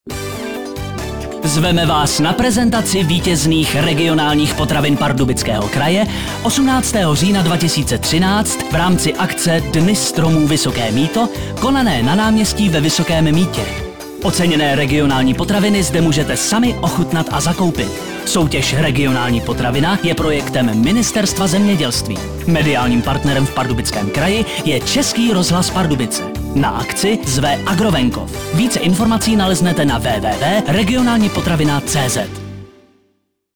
Upoutávky v Českém rozhlase Pardubice na ochutnávky  vítězných regionálních potravin v Pardubickém kraji: